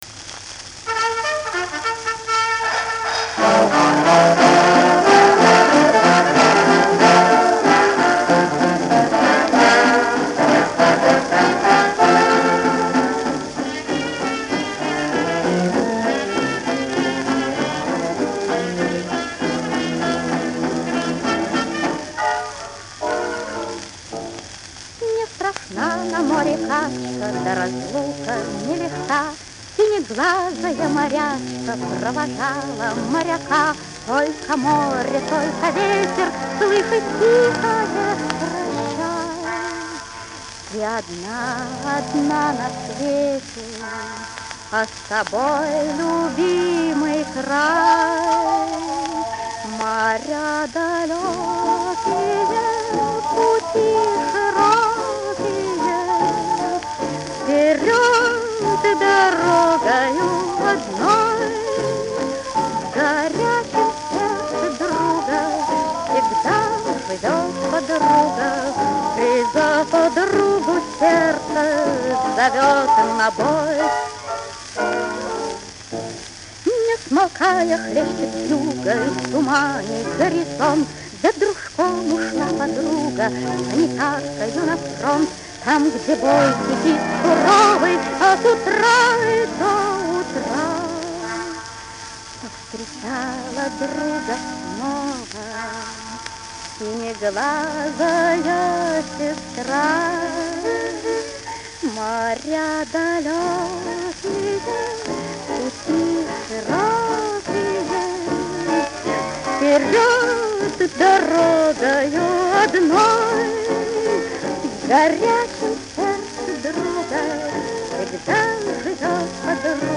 Каталожная категория: Сопрано с джаз-оркестром |
Жанр: Песня
Вид аккомпанемента:    Джаз-оркестр